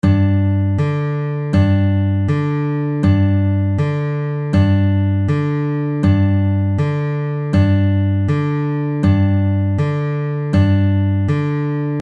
Exercise 4 - Alternate Bass with Pinch
You play exercise 3 and add a pinch on beats 1 and 3.